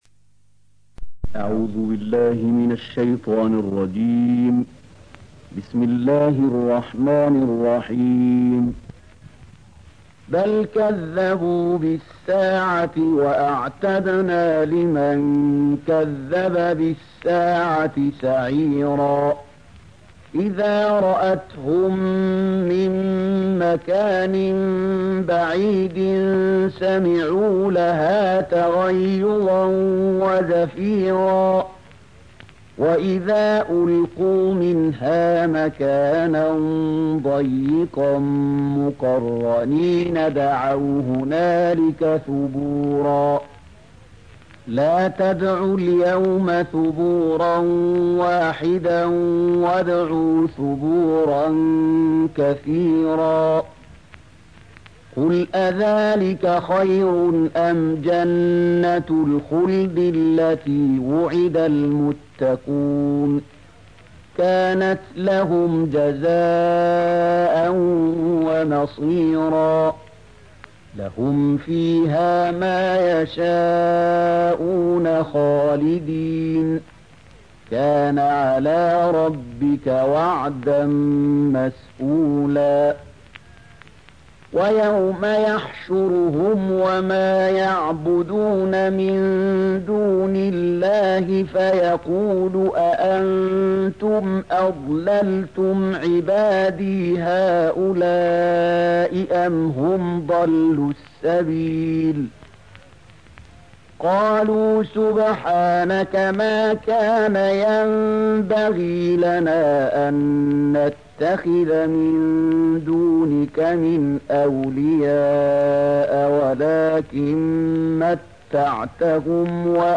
A MARTYR SCHOLAR: IMAM MUHAMMAD SAEED RAMADAN AL-BOUTI - الدروس العلمية - تفسير القرآن الكريم - تسجيل قديم - الدرس 204: الفرقان 11-16